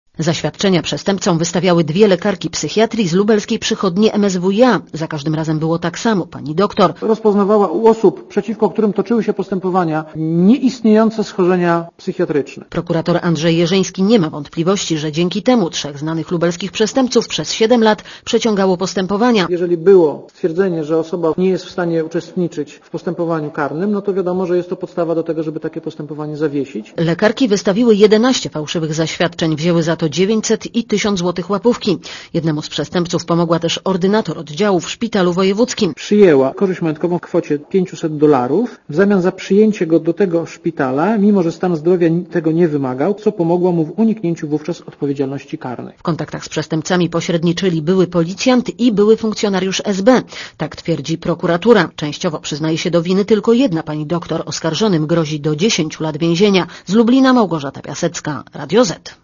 Posłuchaj relacji reporterki Radia Zet (230 KB)